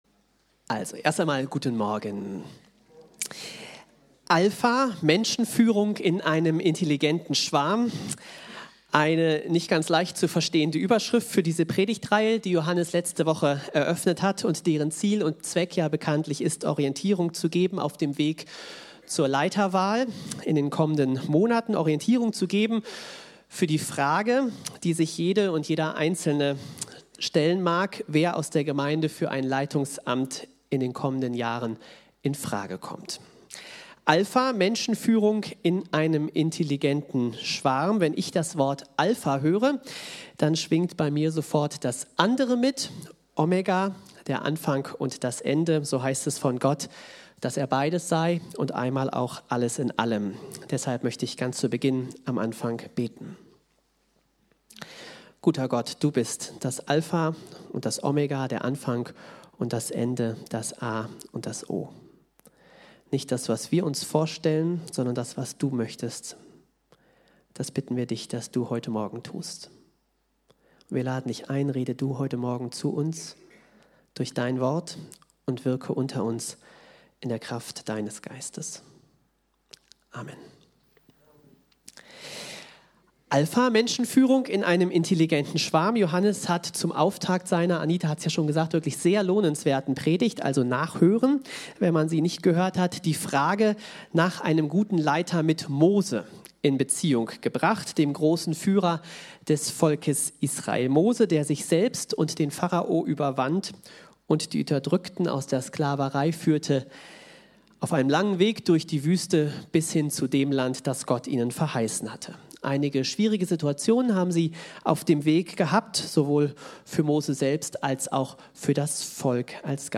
Die Online-Präsenz der freien evangelischen Andreas-Gemeinde Osnabrück
PREDIGTEN